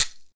percussion 49.wav